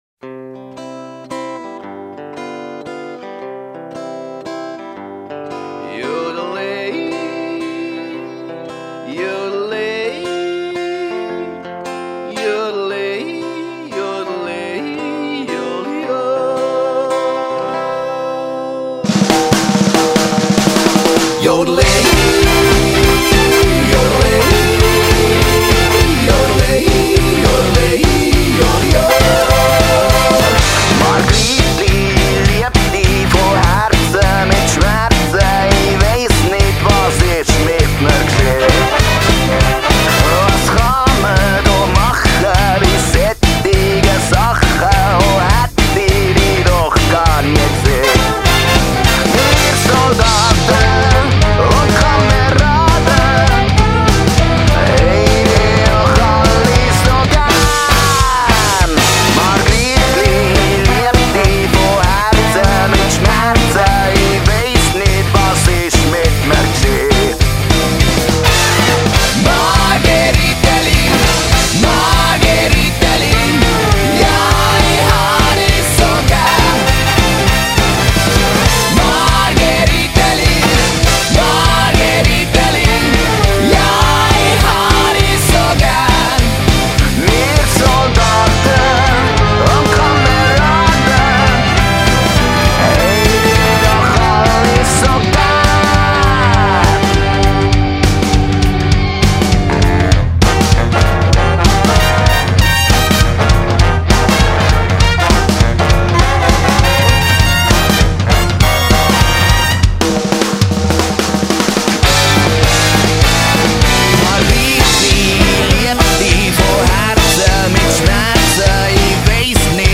Big band punk.
a punk touch combined with a jazzy big band feel